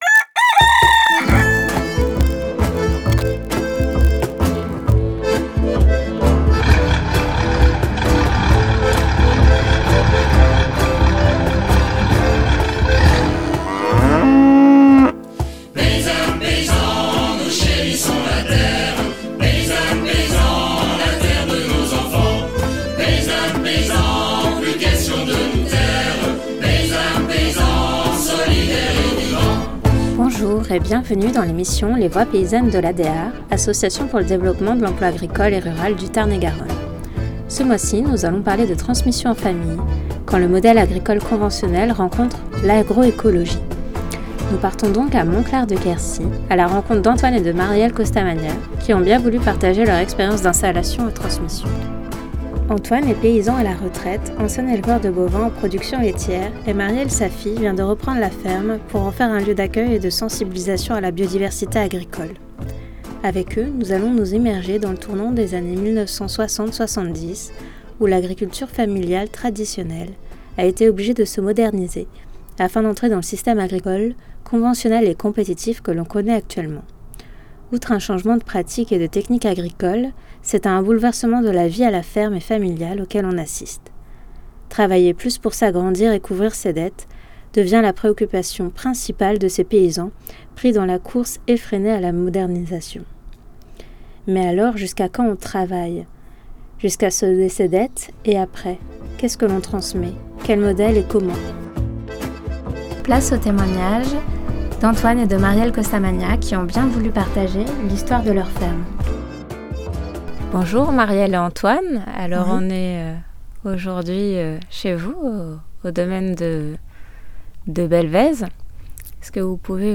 Emission sur le témoignage d’un père et sa fille qui ont vécu ensemble les évolutions et les changements sur leur ferme. Les années 1960-70 amorcent un nouveau tournant pour l’agriculture dite familiale traditionnelle. Avec l’appui de l’état et de sa politique, elle est sommée de se moderniser afin d’entrer dans le système agricole conventionnel et compétitif que l’on connaît de nos jours.